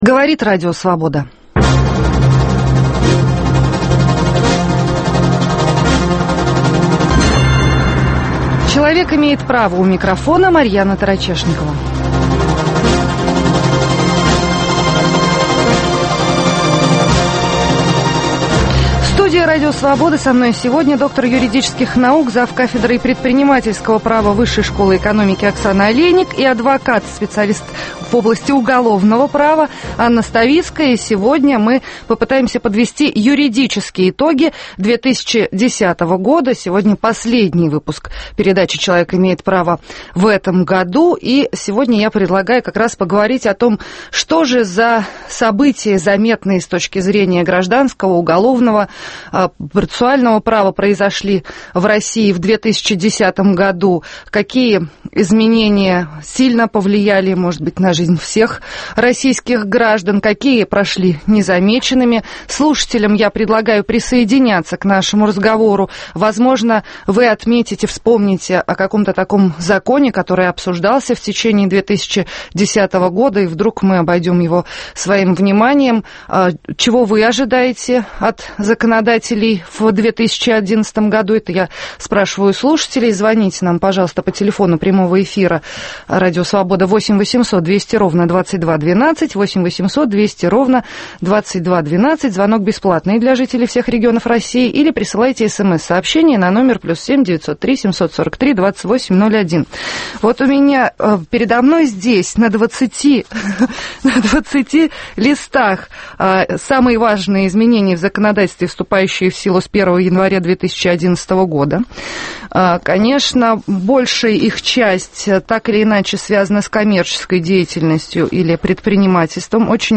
"Юридические итоги 2010 года" в студии РС подводят д.ю.н.